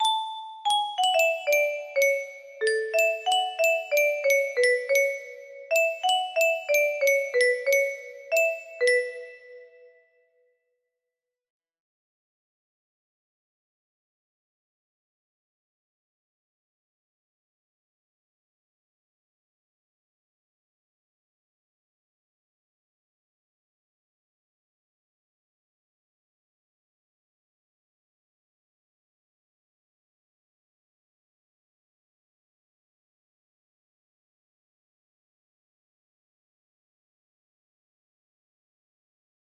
BER music box melody